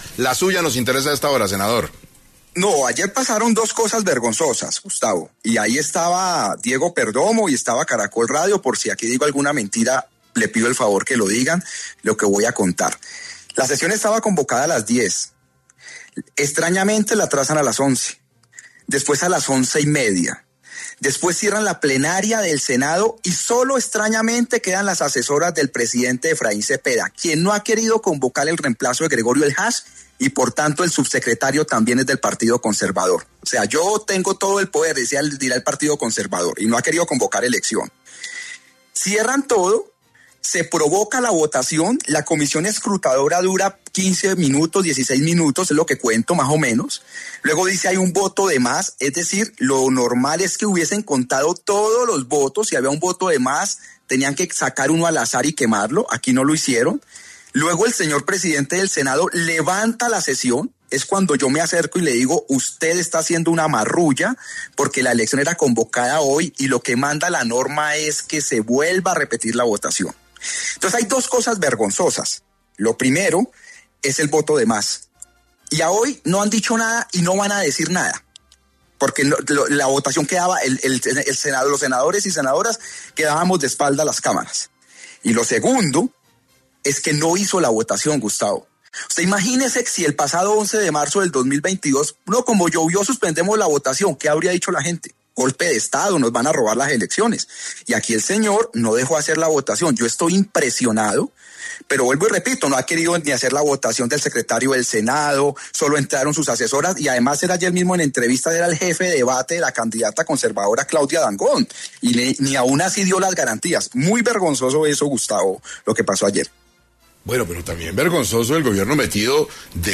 En diálogo con 6AM de Caracol Radio, el senador Ávila argumentó que según lo estipula la norma, el presidente Cepeda debió quemar el voto de más y hacer una votación el día de ayer: